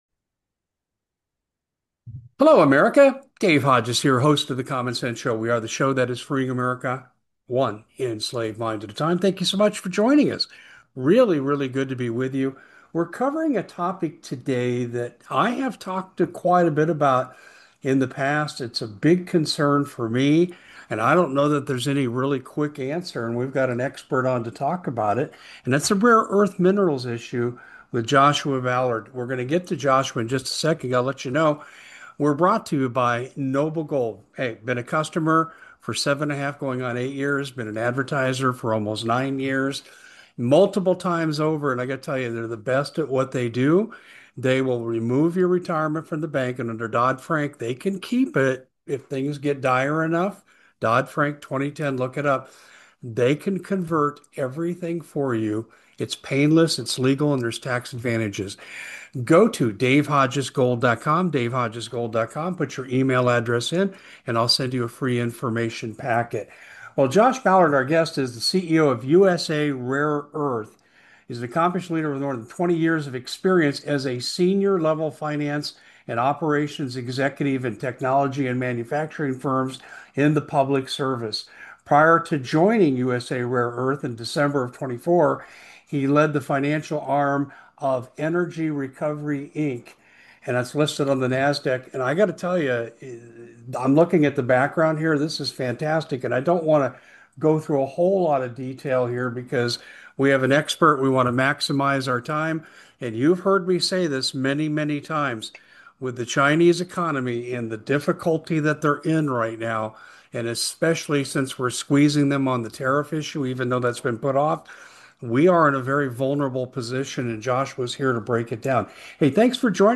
The conversation highlights the need for a surge in production and collaboration with allies to reduce this dependency.